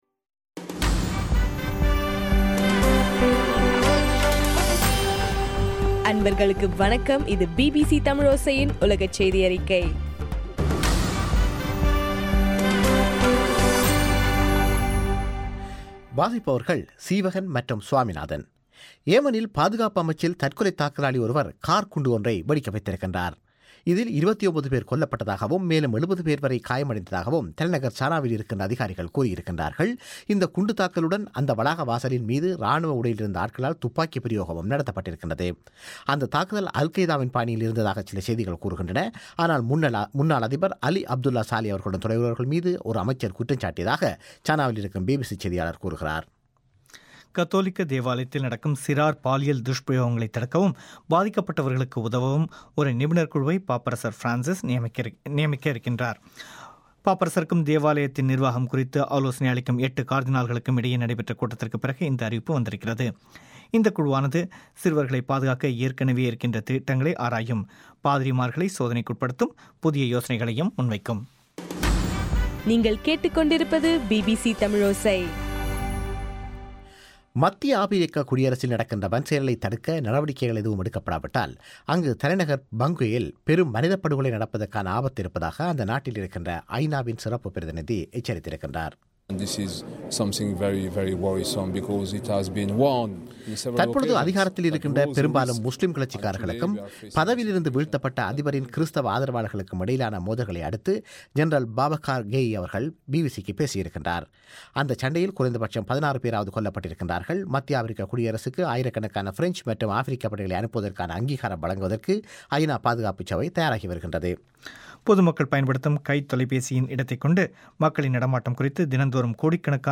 டிசம்பர் 5 பிபிசியின் உலகச் செய்திகள்